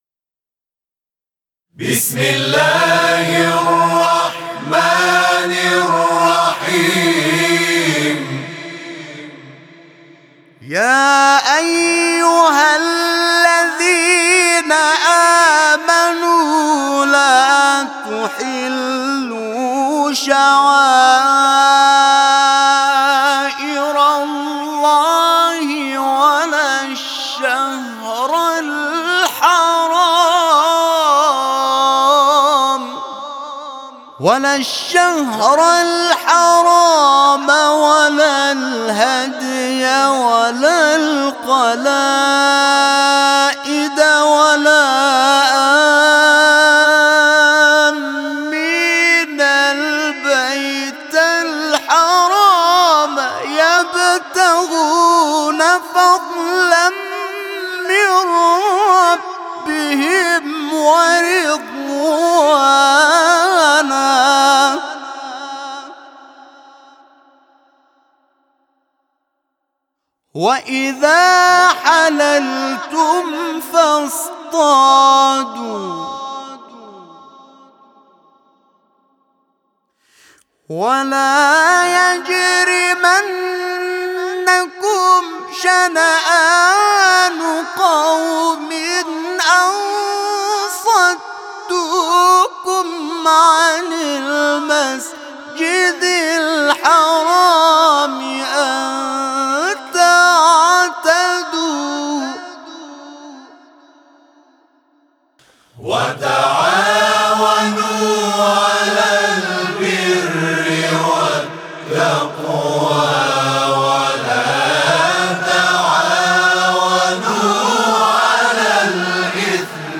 صوت همخوانی آیه 2 سوره مائده از سوی گروه تواشیح «محمد رسول الله(ص)
برچسب ها: سوره مائده ، زندگی با آیه ها ، گروه همخوانی محمد رسول الله (ص)